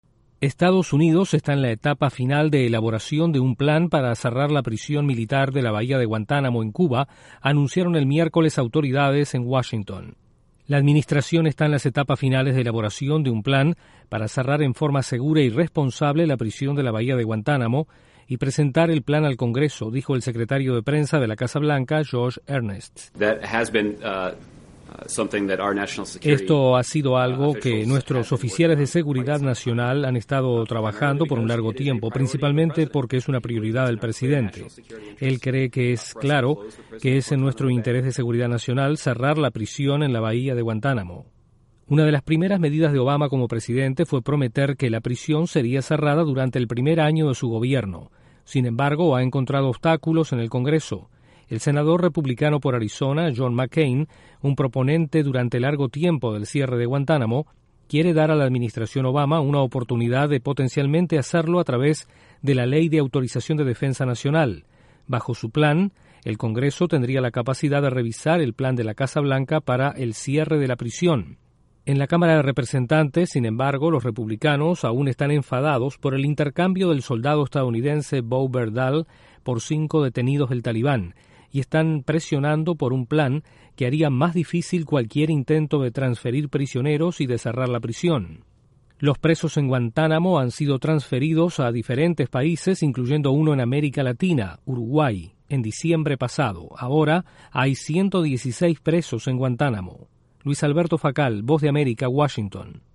Estados Unidos está en etapa final para el cierre de la prisión de Guantánamo, Cuba. Desde la Voz de América en Washington informa